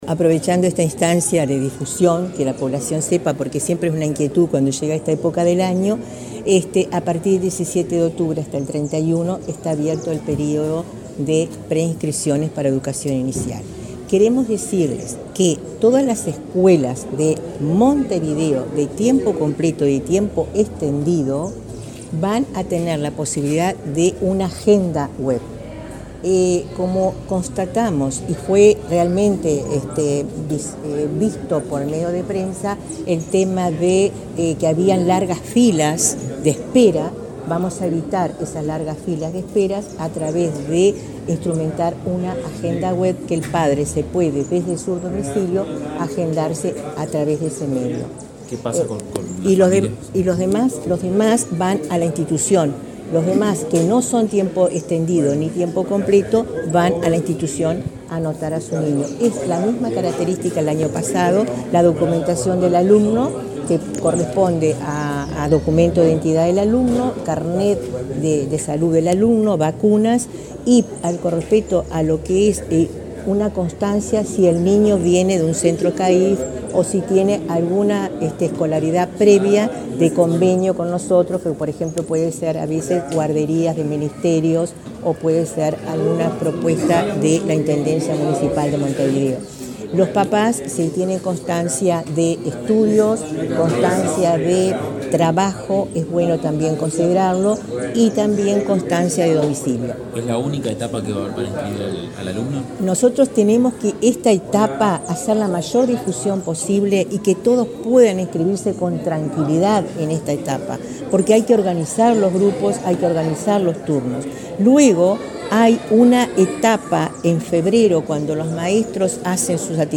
Declaraciones de la directora general de Educación Inicial y Primaria
En el centro n.° 306, Micaela Guyunusa, dialogó con la prensa acerca de la apertura del período de preinscripción para educación inicial.